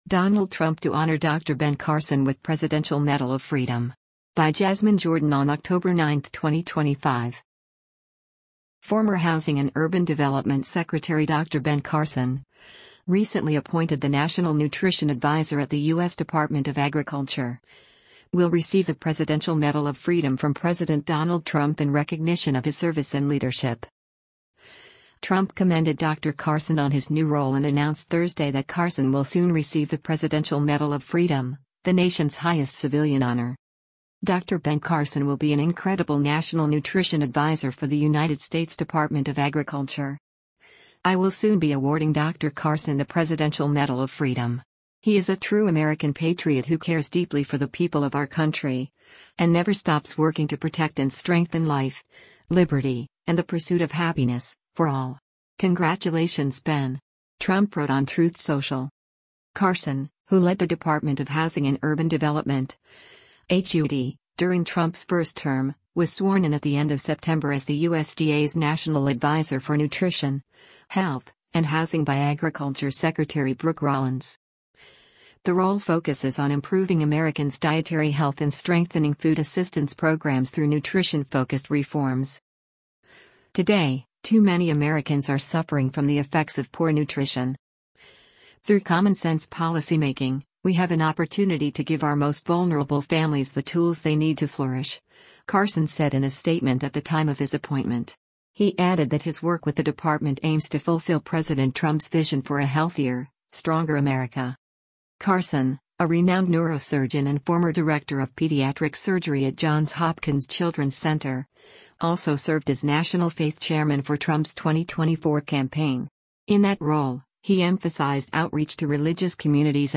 In an interview with Breitbart News Daily, Dr. Ben Carson outlined his priorities in his new role at the Department of Agriculture, emphasizing efforts to improve access to healthy foods for families participating in federal programs such as SNAP. Carson also highlighted the importance of education in encouraging healthier choices and noted that environmental and lifestyle factors significantly influence public health outcomes.